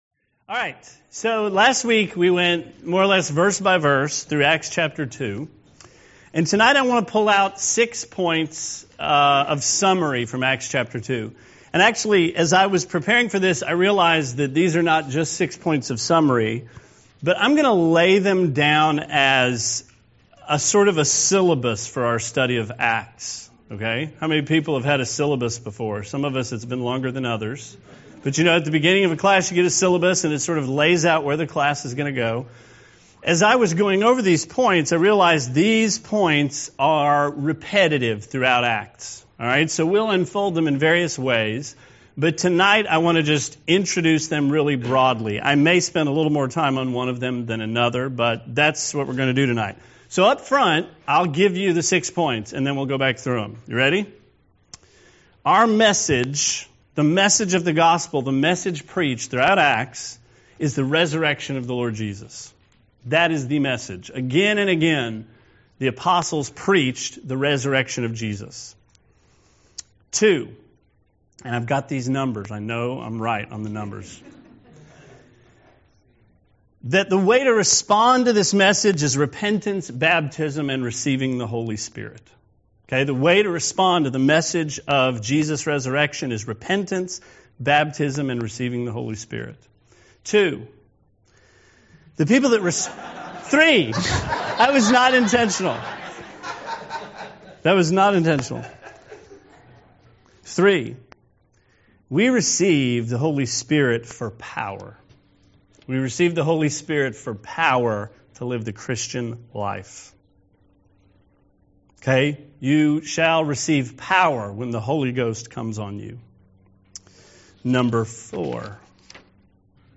Sermon 3/4: Acts: Six Point Syllabus of Study from Chapter 2 – Trinity Christian Fellowship